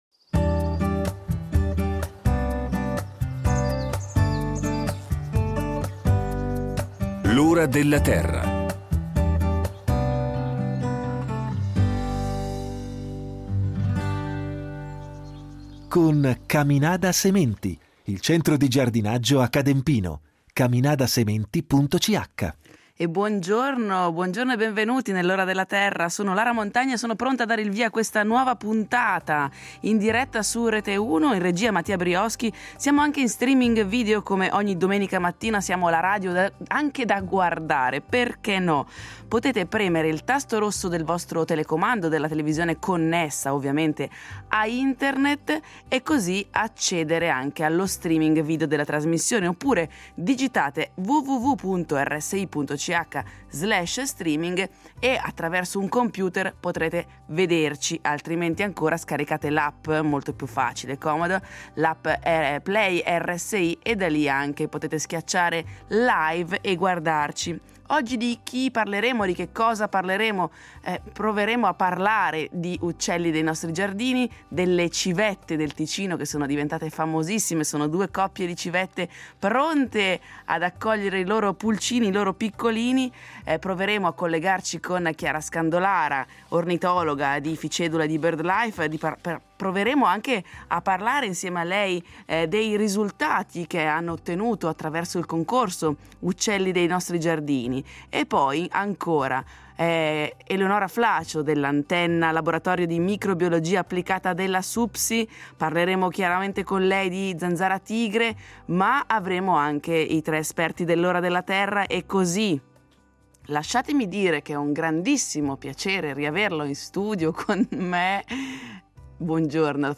Non mancheranno i tre esperti del programma, che risponderanno alle domande del pubblico da casa.